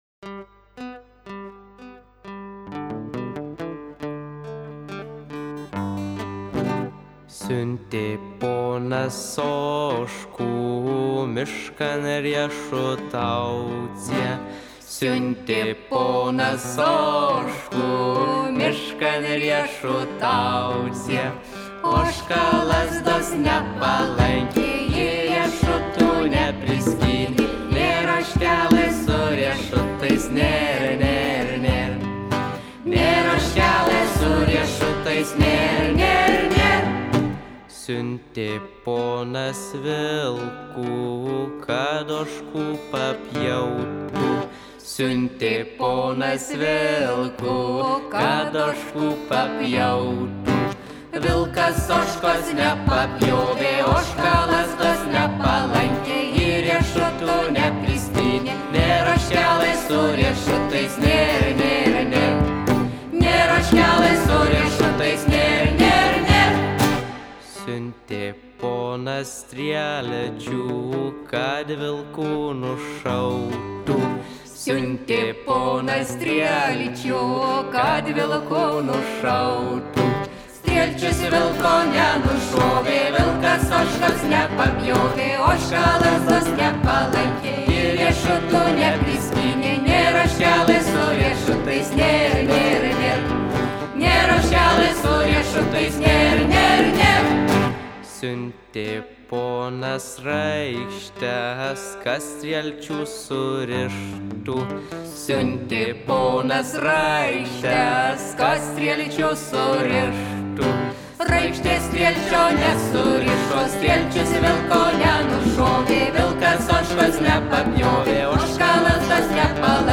ansamblis; aranžuota
Merkinės kultūros namų patalpose, 2011 metais